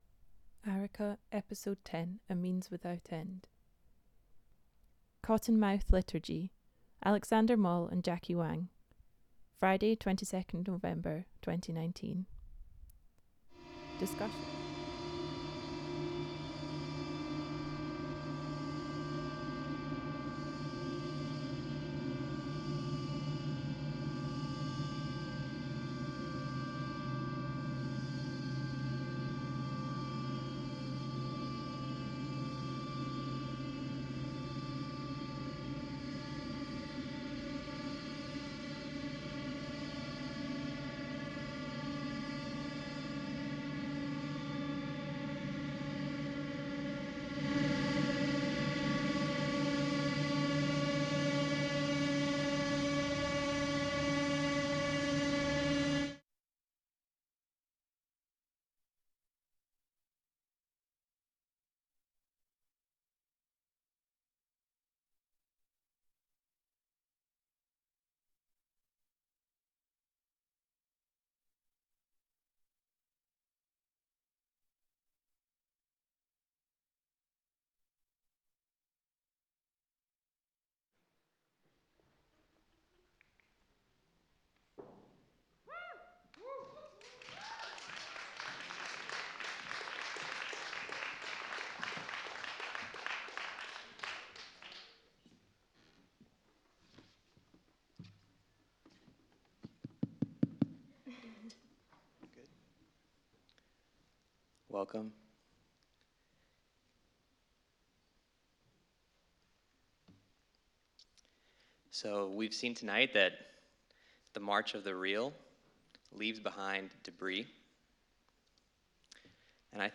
A multi-media harp and spoken-word tribute to the incalculable, the in-deducible, the suspicious static noise that accompanies the voice of truth, and the attempted aberrations in the domain of emerge
Venue Tramway, Glasgow